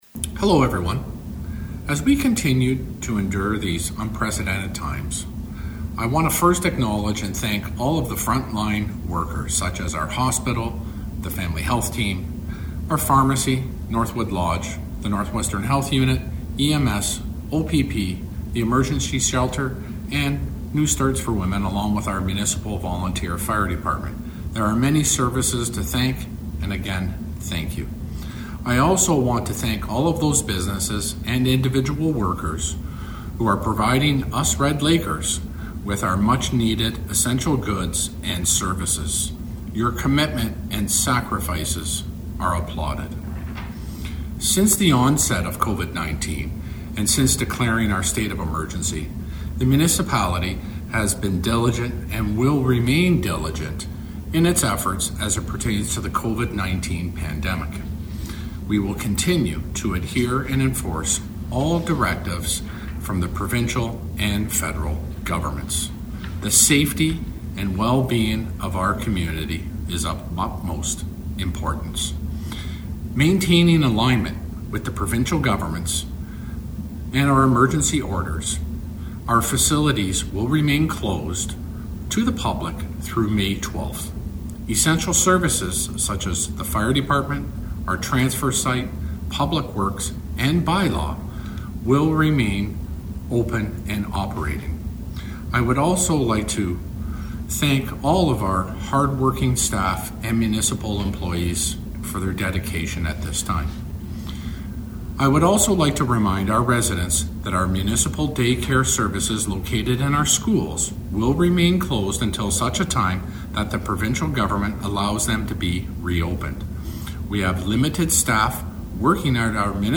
Fred Mota delivered a community update on the CKDR Morning Show Monday and he stated all facilities will remain closed to the public through May 12th.